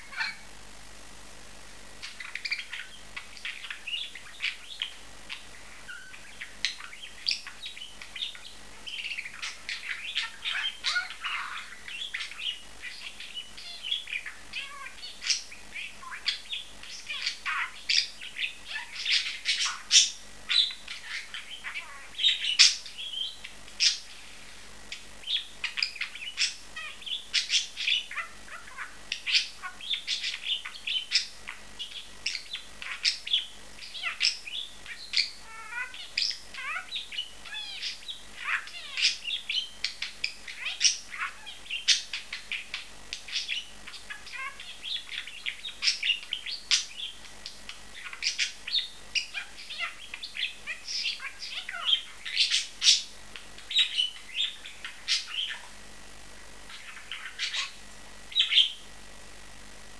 Wellensittich Songs: Musik für unsere Ohren
Hier jetzt einige "Songs" von unseren Sittichen, beim Schlummerstündchen und während des Spielens aufgenommen.
Wave-files ca. 1 MB (lange Songs)
tschirp12.wav